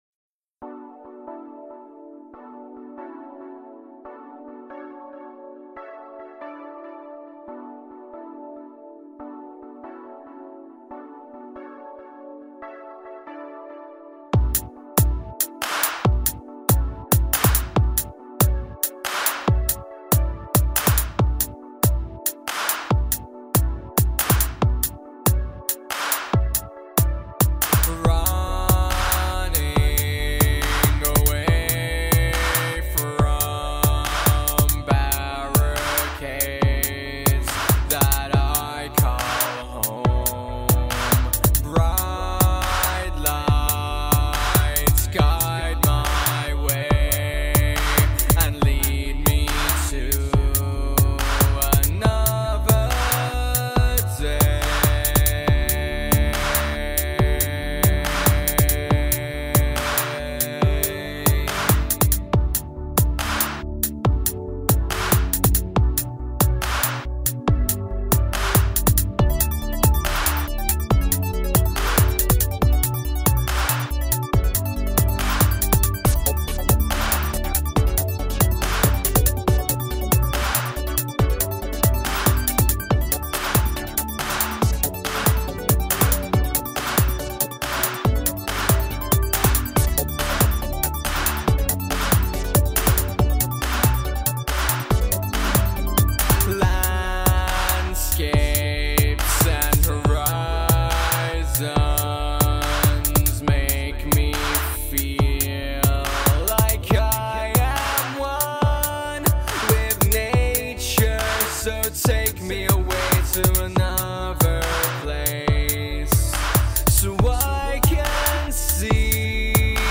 it's electronic and auto tune